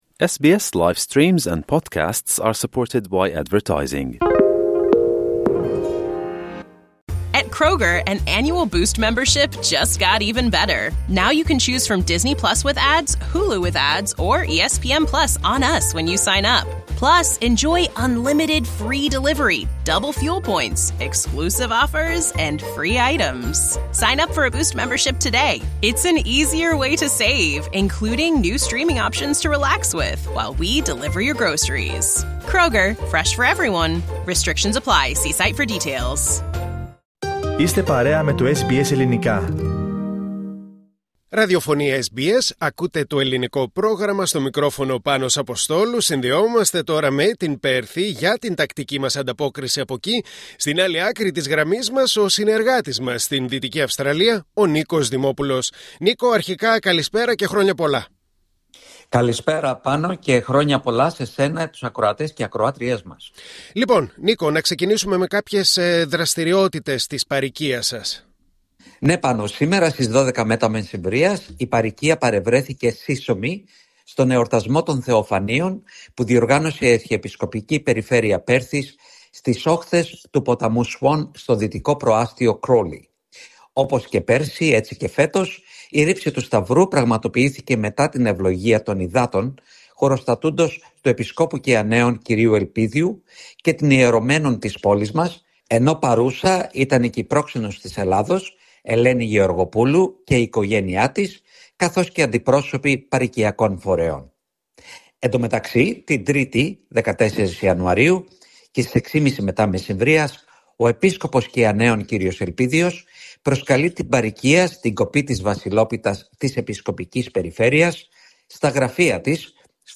Ακούστε την εβδομαδιαία ανταπόκριση από την Δυτική Αυστραλία